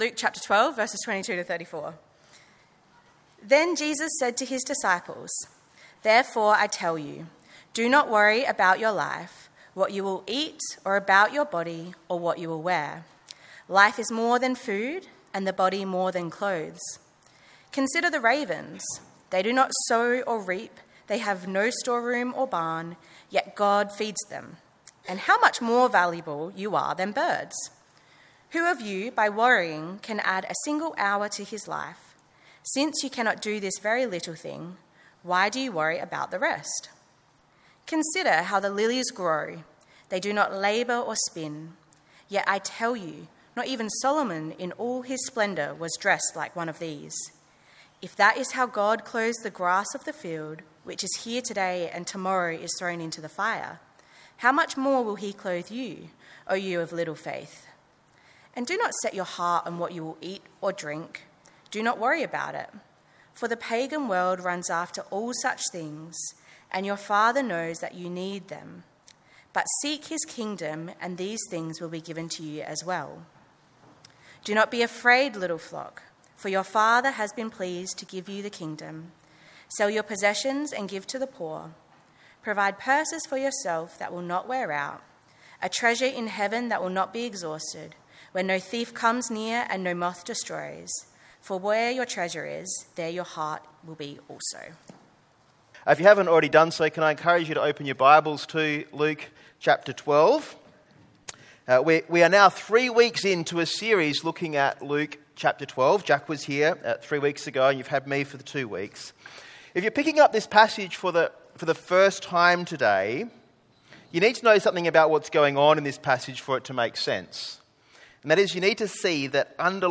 Play Rate Listened List Bookmark Get this podcast via API From The Podcast The sermon podcast for Trinity Church Colonel Light Gardens, a Trinity Network Church located in South Australia.